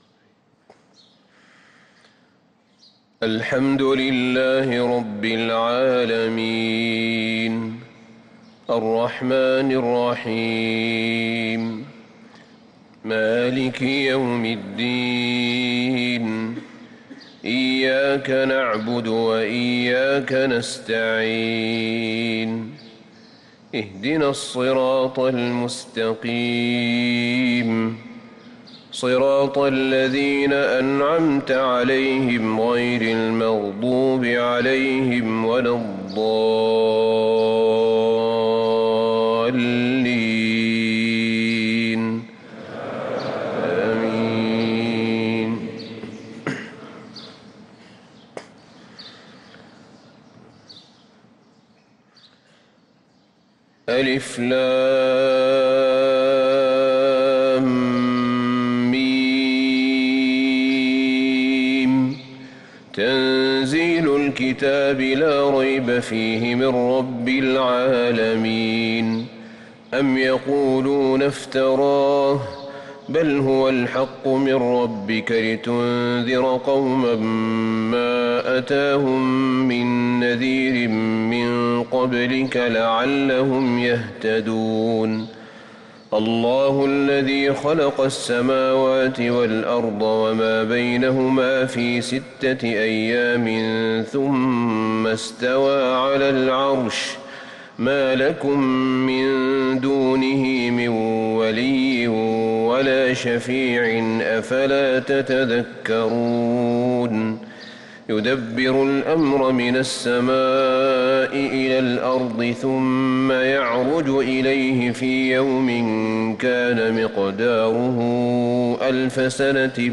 صلاة الفجر للقارئ أحمد بن طالب حميد 19 ربيع الآخر 1445 هـ
تِلَاوَات الْحَرَمَيْن .